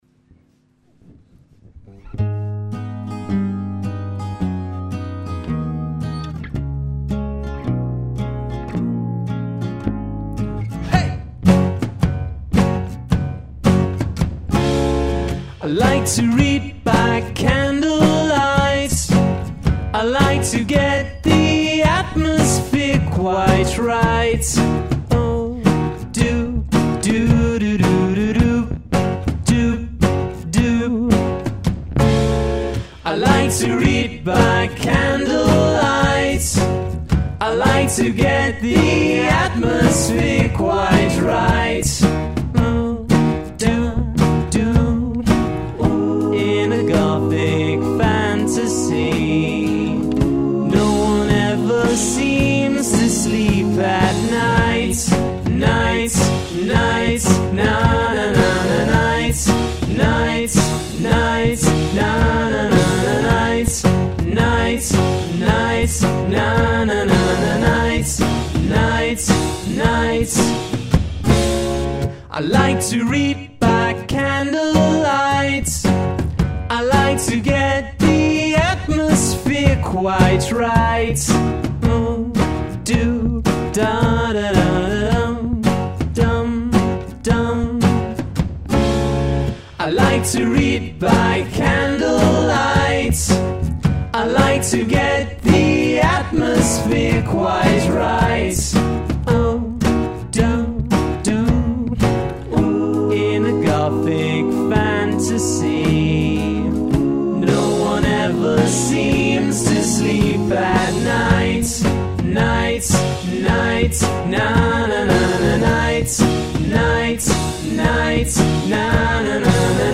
batteria
chitarra elettrica